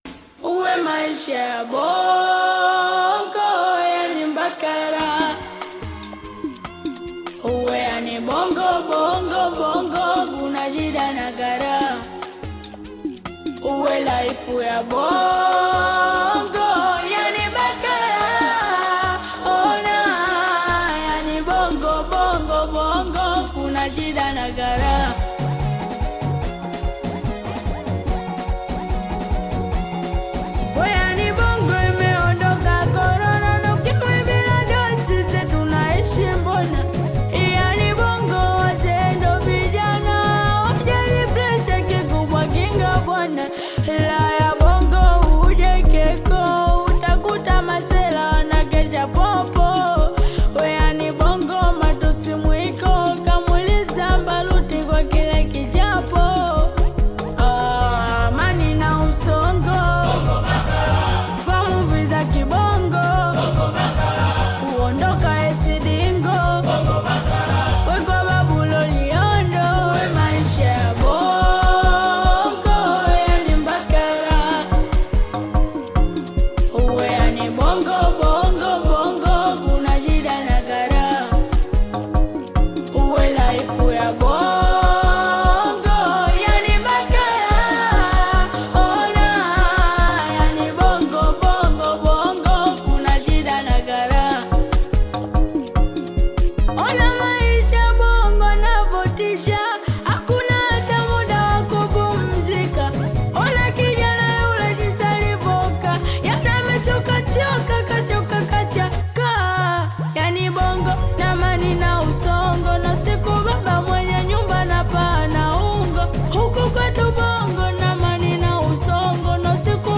Singeli music track
Tanzanian Bongo Flava artist